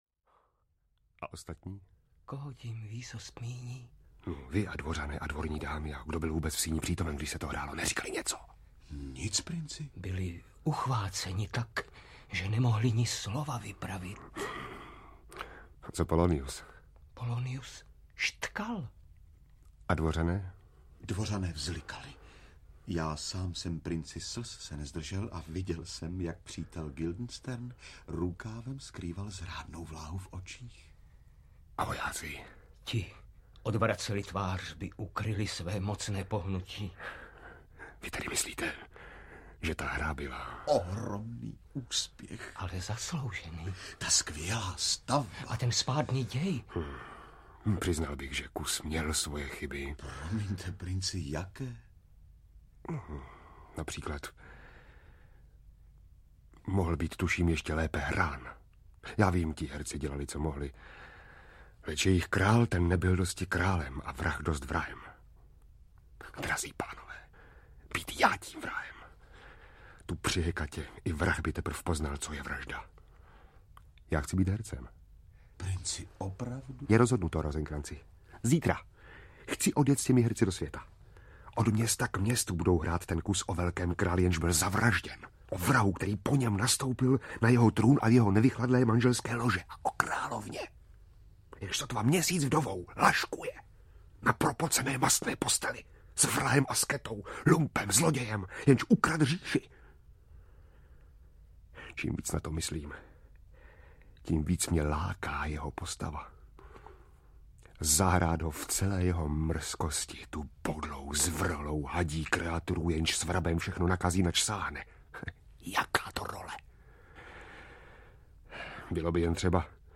Neobyčejný život. audiokniha
V říjnu 1989 nahrávala redakce mluveného slova Supraphonu ve studiu Lucerna pásmo Jana Kopeckého "Svět podle Karla Čapka" k 100. výročí Čapkova narození.
Ukázka z knihy